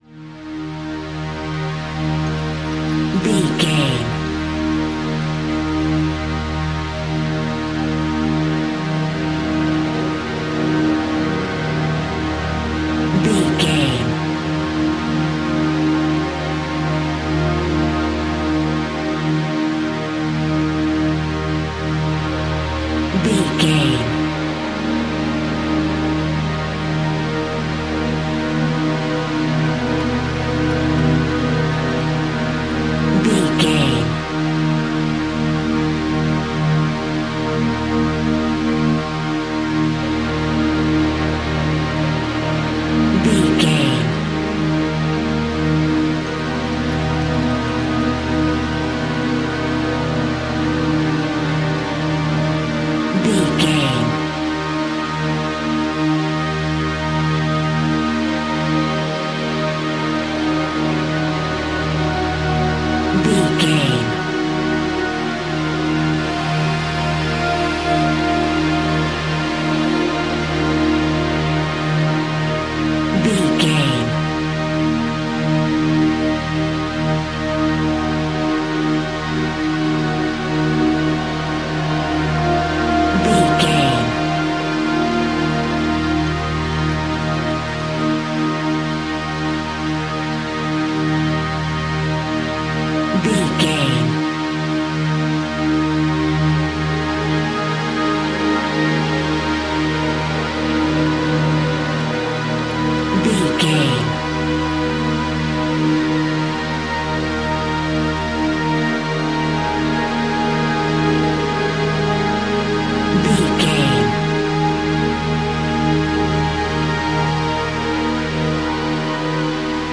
Aeolian/Minor
E♭
Slow
ominous
dark
suspense
eerie
synthesiser
strings
Synth Pads
atmospheres